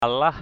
/a-laɦ/